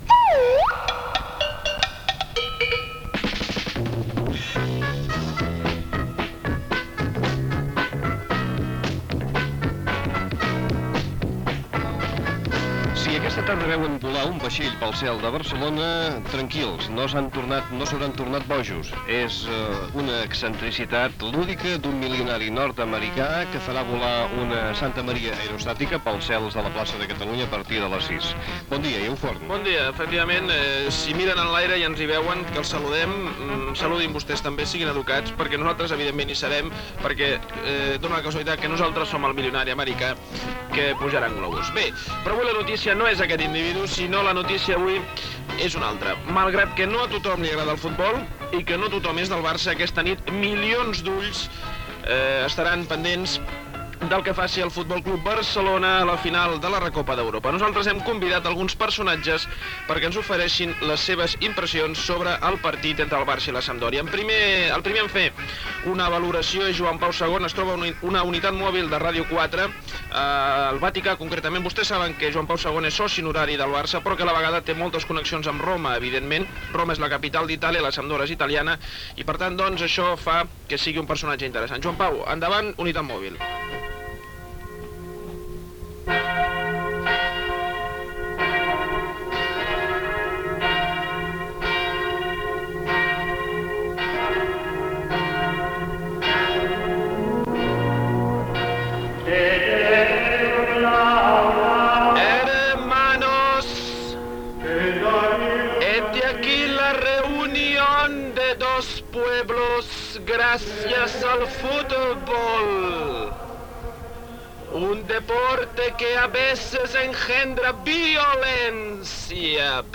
"De bon matí, de bon humor" espai humorístic dedicat a la final de la Recopa de Futbol masculí entre el F.C. Barcelona i la Sampdoria, al Wankdorfstadion de Berna. Amb imitacions de Joan Pau II, Felipe González, José María García, etc.
Info-entreteniment
FM